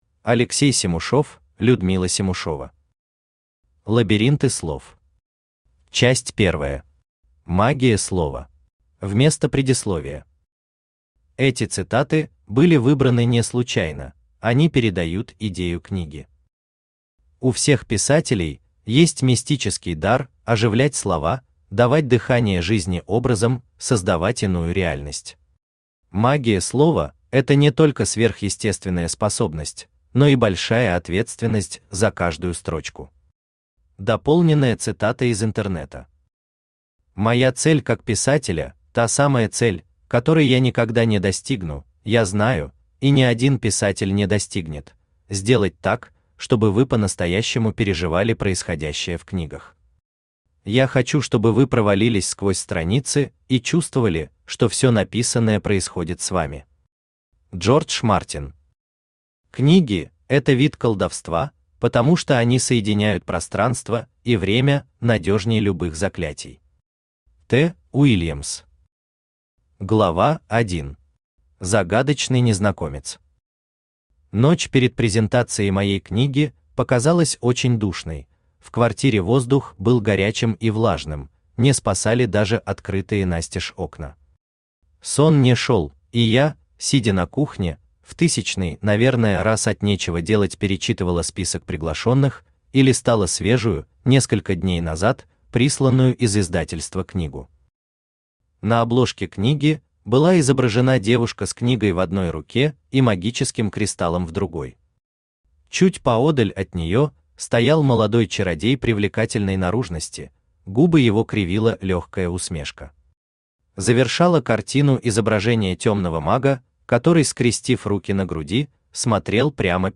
Аудиокнига Лабиринты слов. Часть первая. Магия слова | Библиотека аудиокниг
Aудиокнига Лабиринты слов. Часть первая. Магия слова Автор Алексей Семушев Читает аудиокнигу Авточтец ЛитРес. Прослушать и бесплатно скачать фрагмент аудиокниги